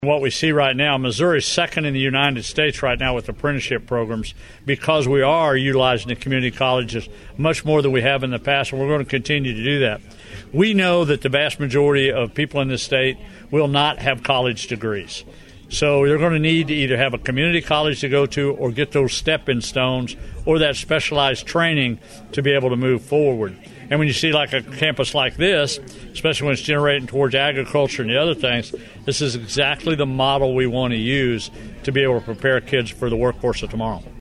After signing House Bill 574 at Trenton on June 10th, Governor Mike Parson spoke about the role of community colleges, like North Central Missouri College, in his workforce development plan.